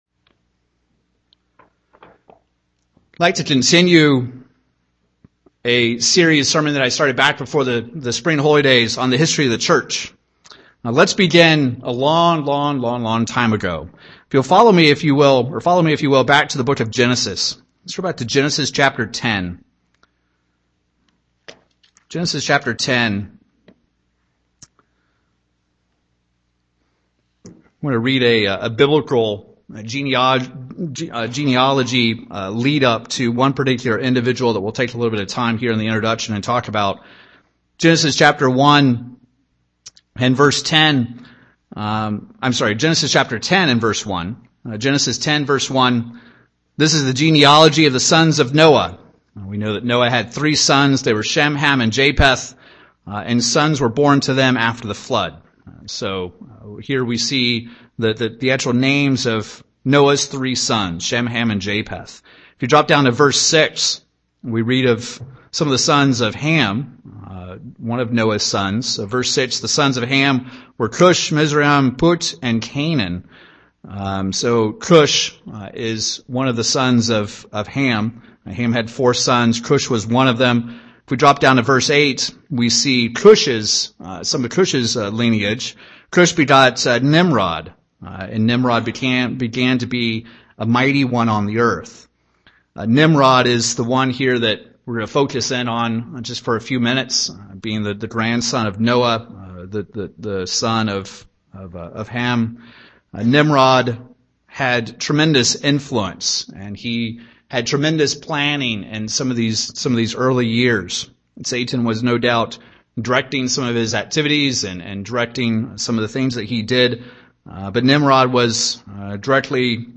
Two versions of christianity can be tracked through time. In this part 4 sermon on the History of the Church, let's compare and contrast the True Church with Satan's version of Christianity down through the pages of history.